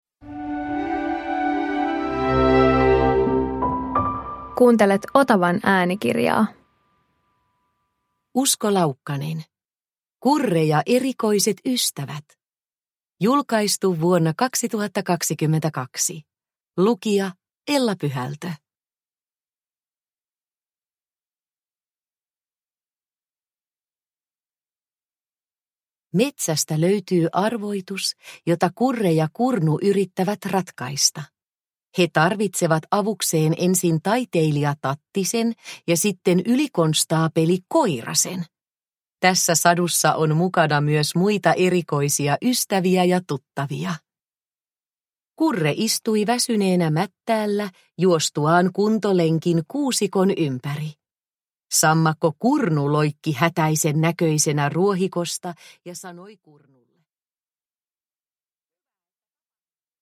Kurre ja erikoiset ystävät – Ljudbok – Laddas ner